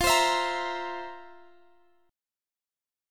Listen to C7/F strummed